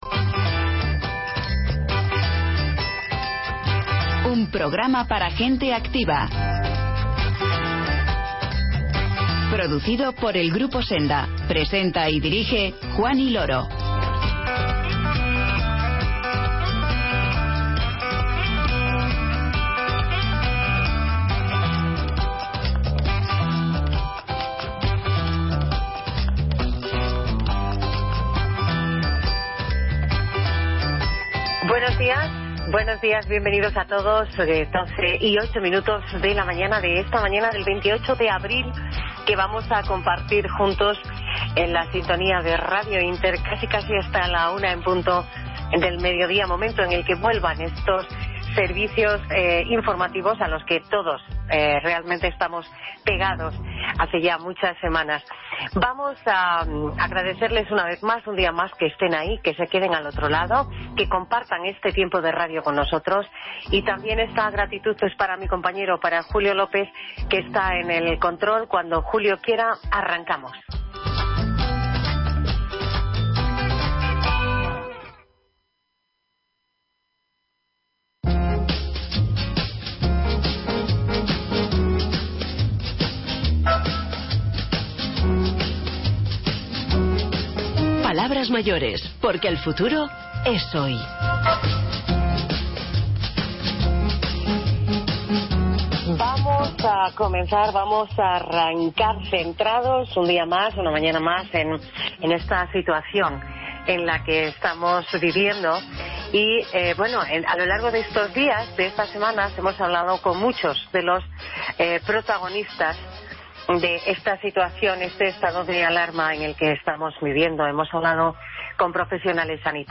Despedimos el programa con el humor